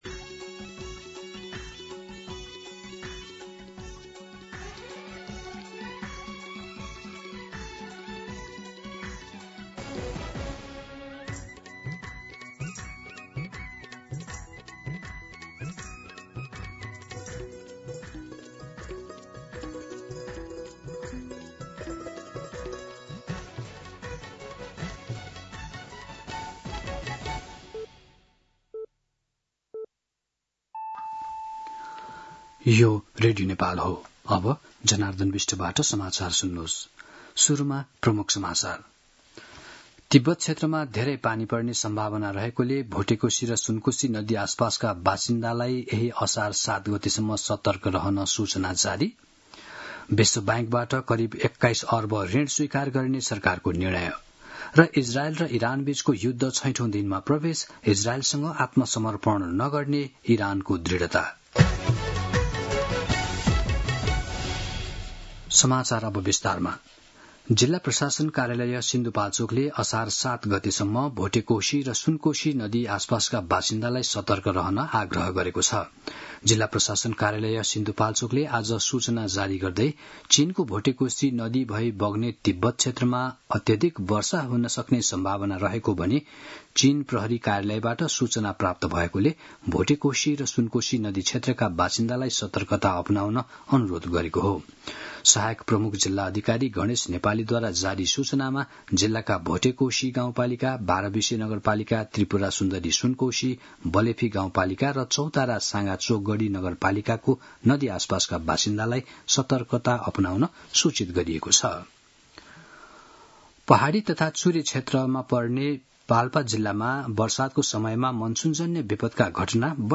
दिउँसो ३ बजेको नेपाली समाचार : ४ असार , २०८२